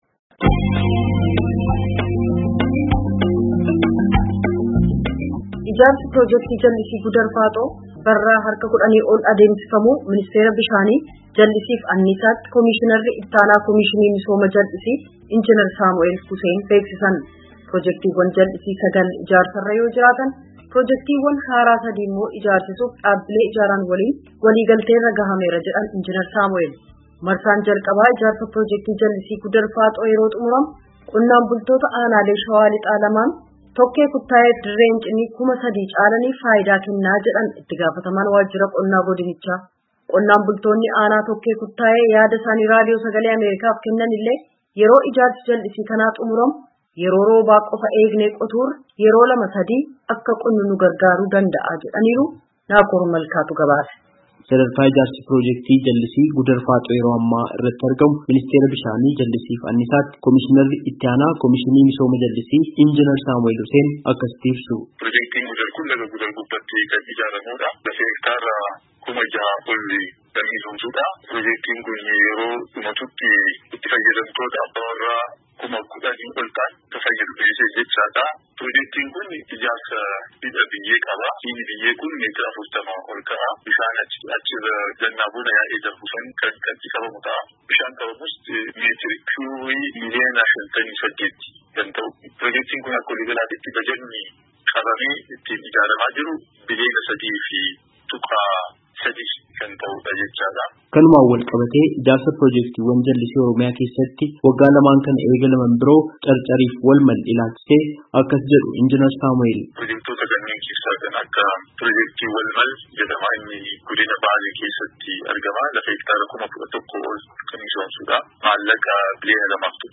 Qonnaan bultootni aanaa Tokkee Kuttaayee yaada isaanii Raadiyoo Sagalee Ameerikaatiif kennanillee, yeroo ijaarsi jallisii kanaa xumuramu Waqtii Roobaa qofaa eegnee qotuurra yeroo lama sadii akka qotnu nu gargaaruu danda’a jedhan.
Gabaasaa guutuu caqasaa